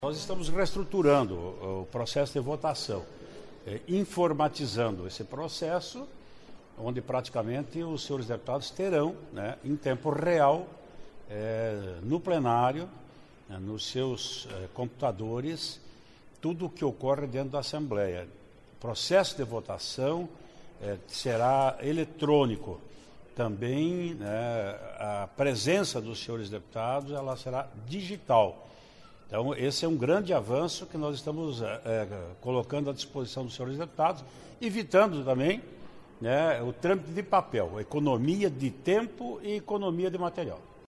Presidente da Assembleia Legislativa  explica que novos  equipamentos para registro de presenças e votações jno Plenário vão dar mais eficácia ao processo de votação e trazer economia  de papel.